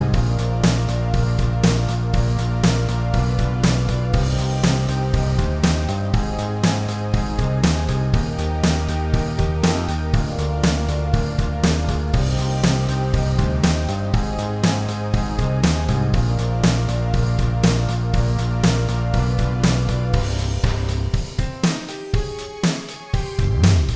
Minus Lead And Solo Guitar Rock 3:48 Buy £1.50